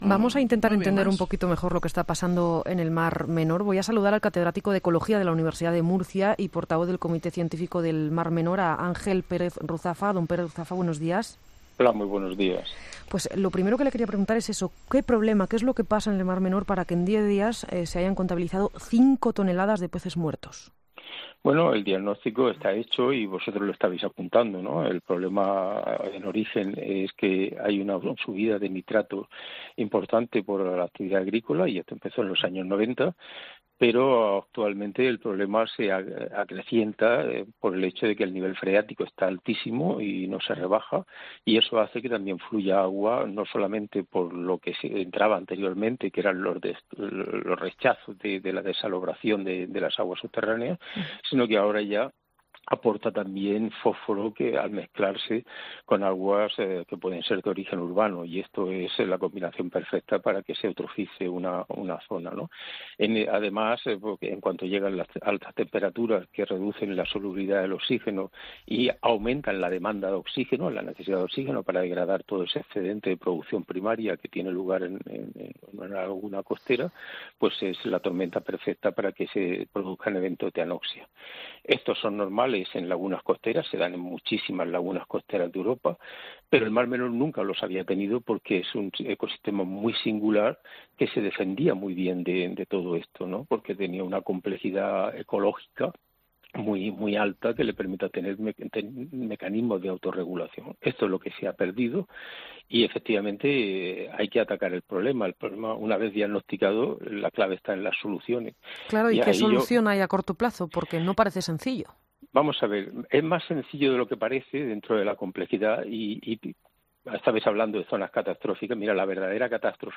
Experto en Ecología, sobre el Mar Menor: “La catástrofe es que es la política se imponga a la gestión"